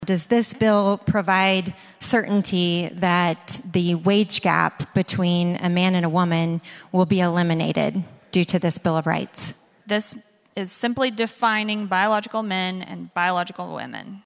CLICK HERE to listen to commentary from State Senator Carri Hicks and Senator Jessica Garvin.
House members approved a bill creating a so-called “Women’s Bill of Rights.” State Senator Carri Hicks asked sponsor Senator Jessica Garvin of Duncan about the bill.